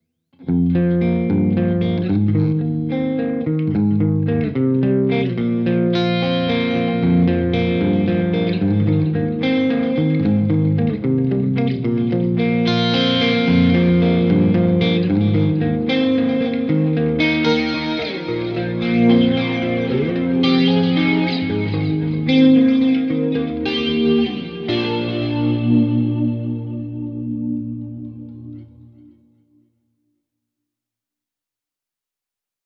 This Amp Clone rig pack is made from a Mesa Boogie JP2C, Mark IV, Mark V, Mark VII and a Mesa Triaxis preamp head.
RAW AUDIO CLIPS ONLY, NO POST-PROCESSING EFFECTS